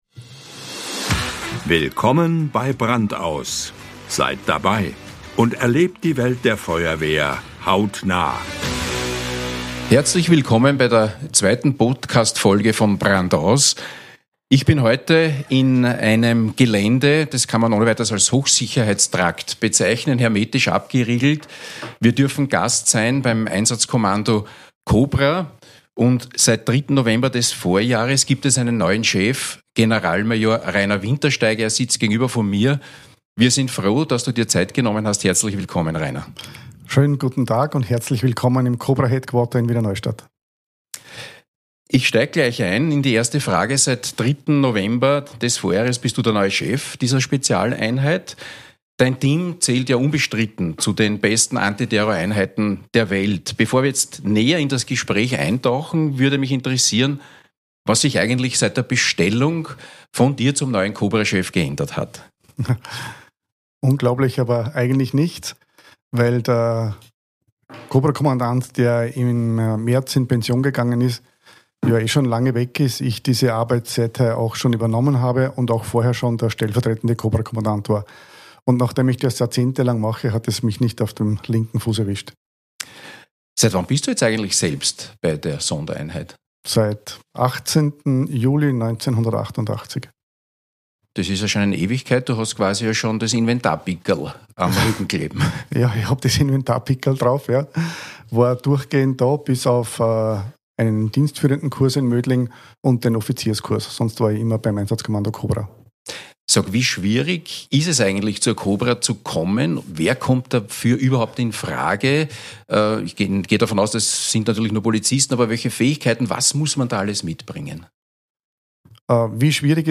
Und auch über die Zusammenarbeit der „Cobra“ mit den Feuerwehren. 30 spannende Gesprächsminuten.